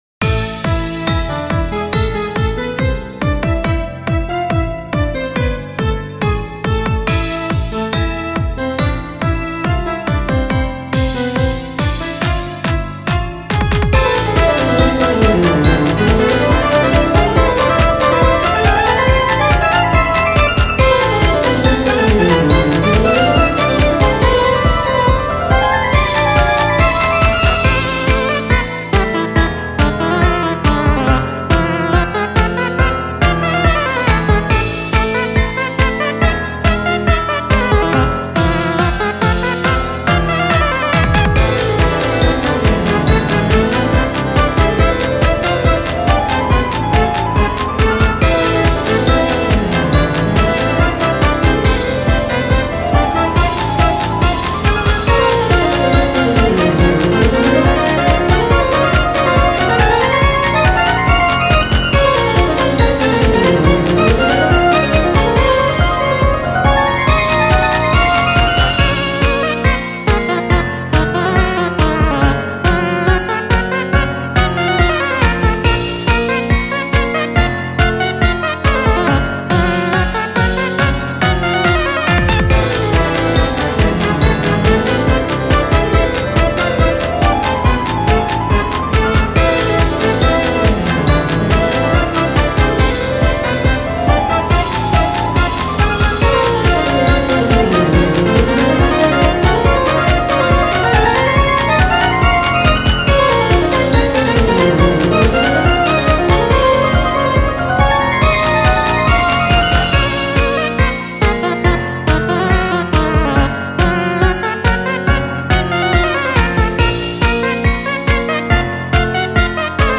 MUSIC -戦闘向け-
ogg版   かわいげのある邪悪さ。